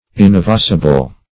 Search Result for " inevasible" : The Collaborative International Dictionary of English v.0.48: Inevasible \In`e*va"si*ble\, a. Incapable of being evaded; inevitable; unavoidable.